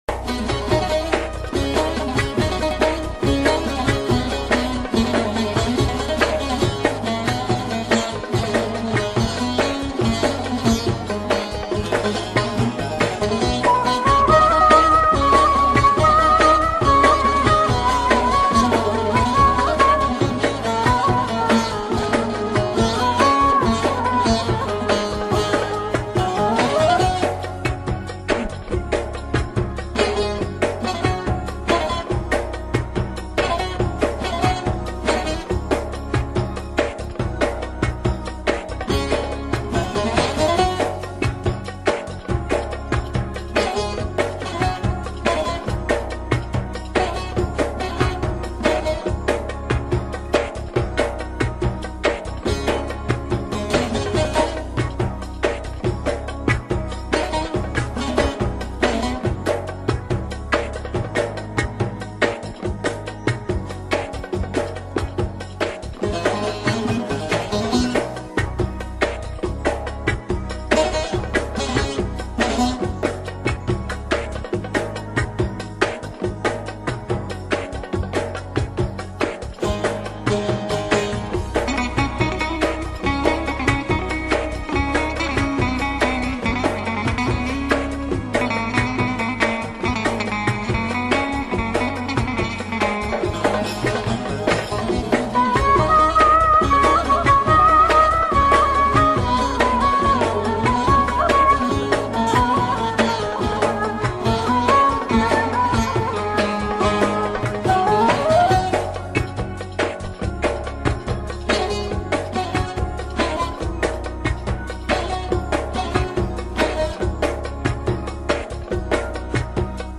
thm: karadır kaşların ferman yazdırır bu aşk beni diyar diyar gezdirir